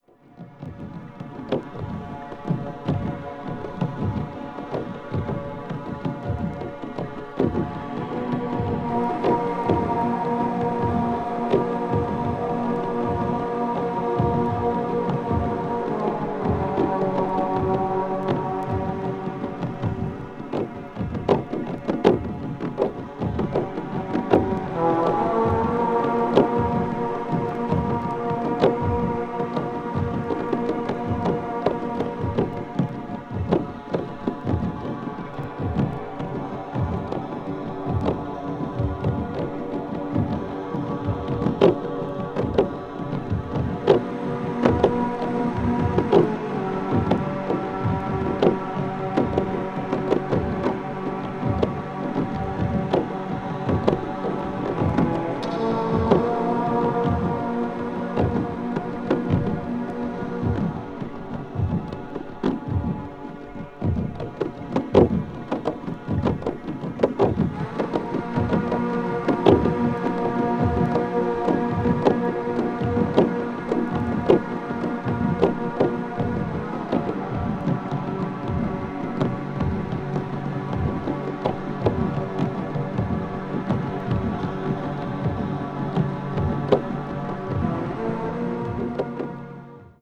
ambient   avant garde   electronic   experimental   minimal